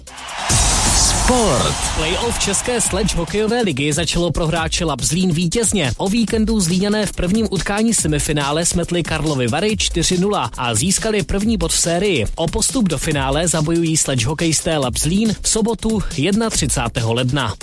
Reportáž z prvního semifinálového utkání konaném v Ostrově.